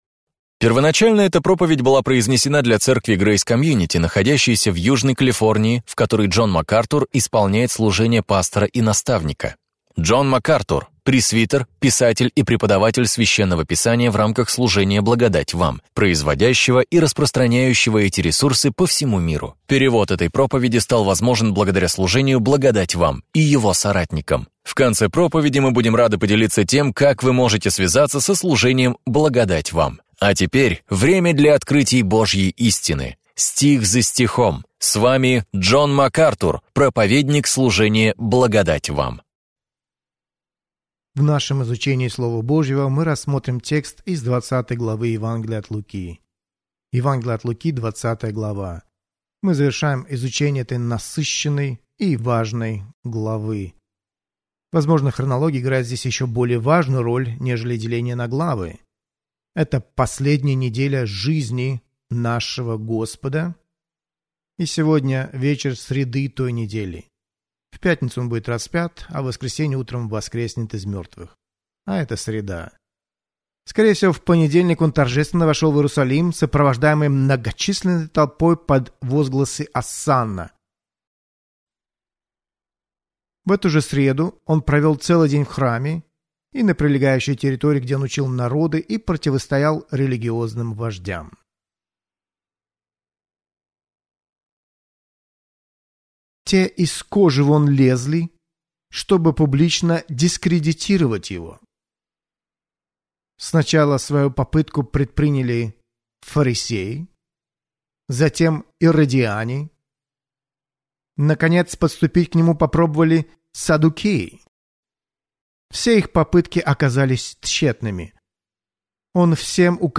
Об этом и пойдет речь в проповеди Джона Макартура «Как общаться с еретиком». Вы увидите изнанку одного из острых конфликтов Христа с набожными врагами истины и Евангелия.